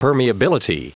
Prononciation du mot permeability en anglais (fichier audio)
Prononciation du mot : permeability